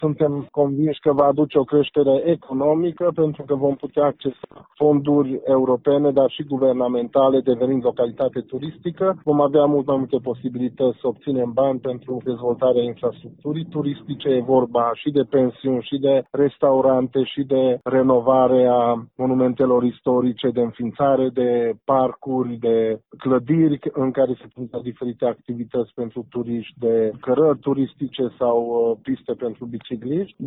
Sofalvi Szabolcs a subliniat că titlul de stațiune turistică va aduce fonduri pentru dezvoltare, dar și beneficii suplimentare agenților economici care vor avea mai mulți clienți: